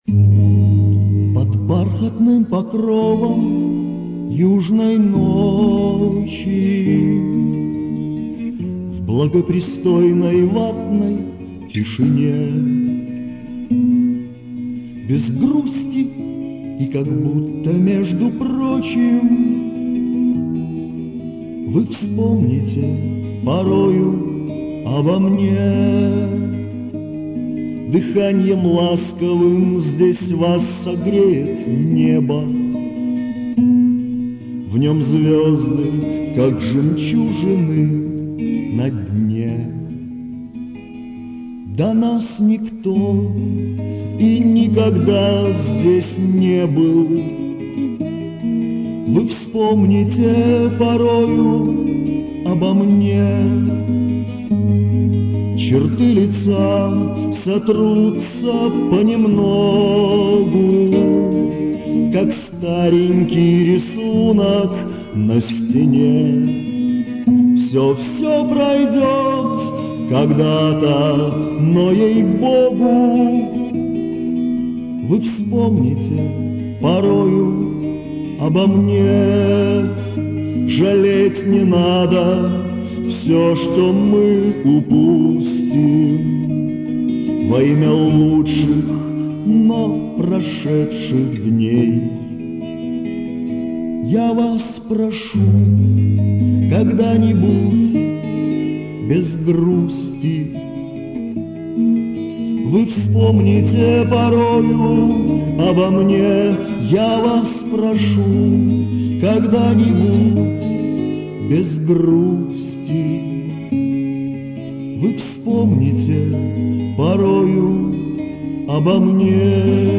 Романс